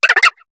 Cri de Flotajou dans Pokémon HOME.